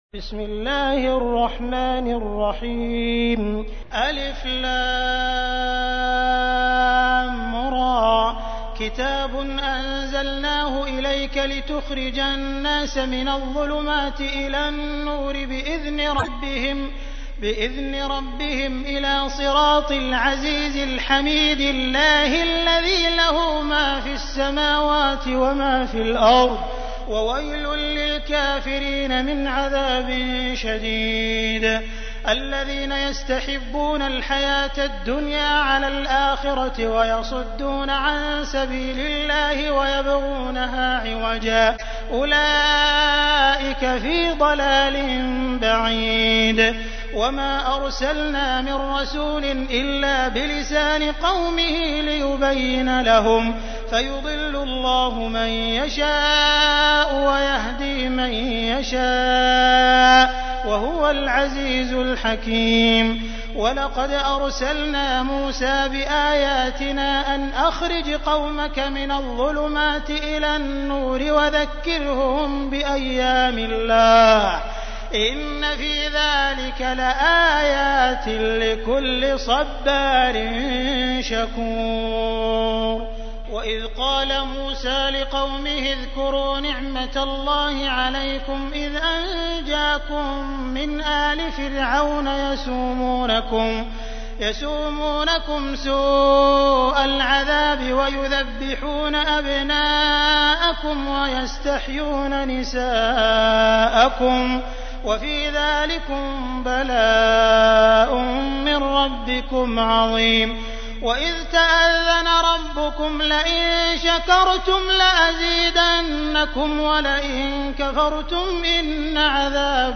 تحميل : 14. سورة إبراهيم / القارئ عبد الرحمن السديس / القرآن الكريم / موقع يا حسين